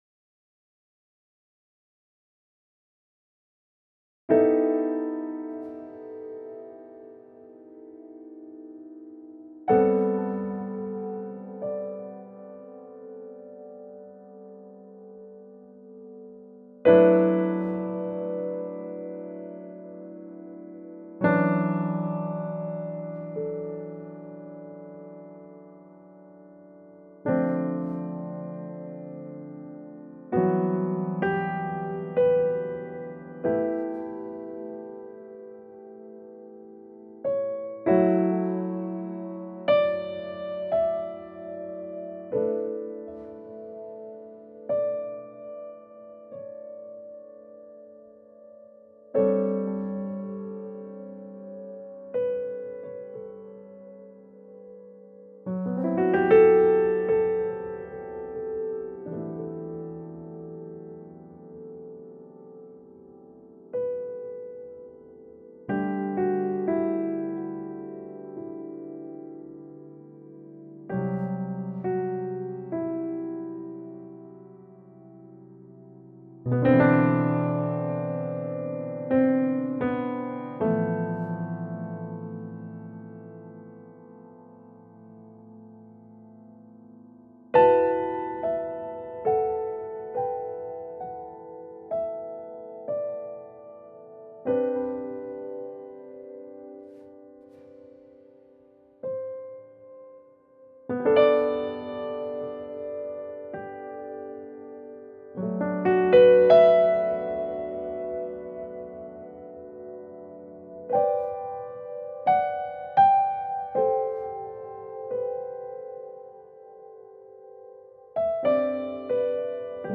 Austere, personal, dreamy music.
Tagged as: Ambient, Other